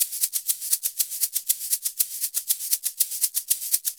Shaker_ ST 120_1.wav